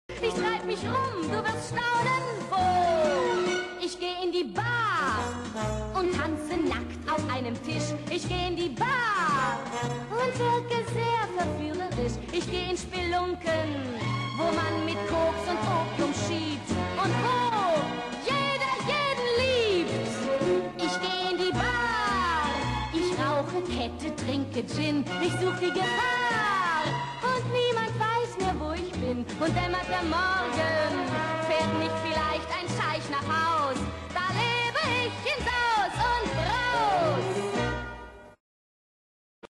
Musikalisches Lustspiel in sechs Bildern